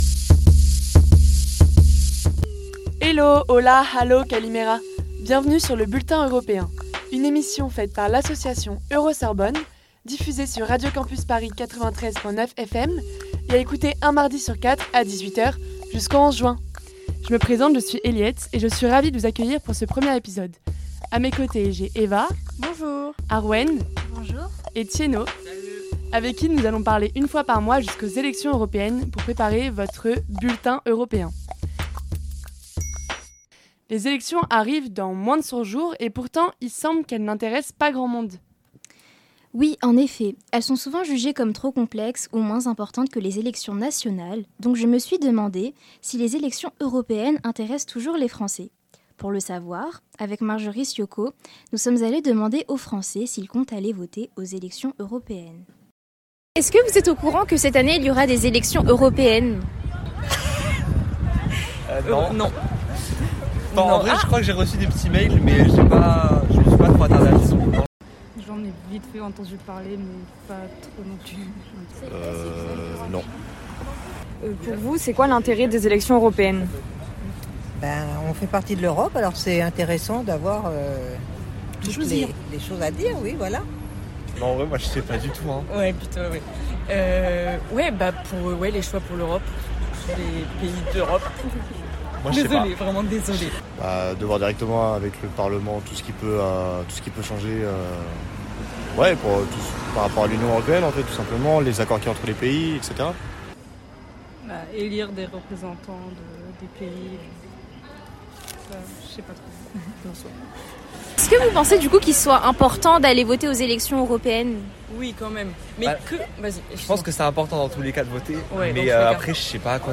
Hello, Hola, Hallo, Kalimera ! Bienvenue dans votre bulletin européen !
Type Magazine Société